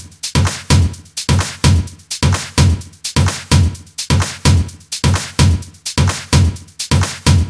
VDE 128BPM Notice Drums 6.wav